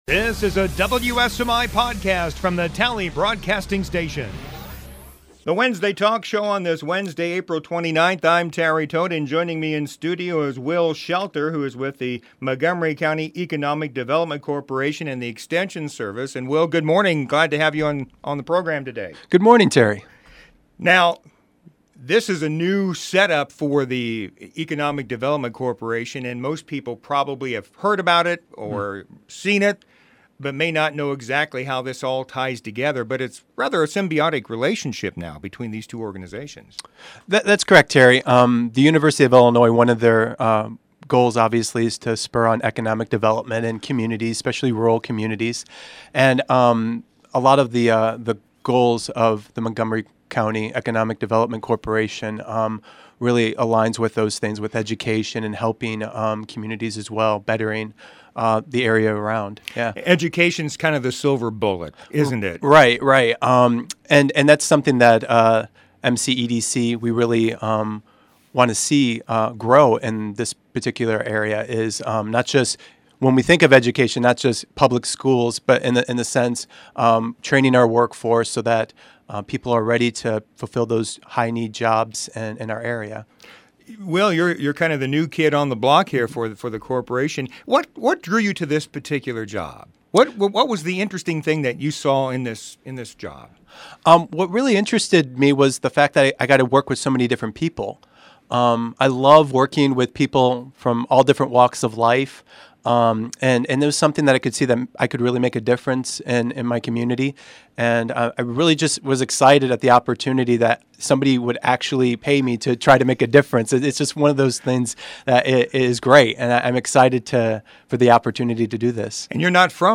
Wednesday Talk Wednesday Talk Show "MCEDC Programs" 04/29/2015 Wednesday Talk Show Guest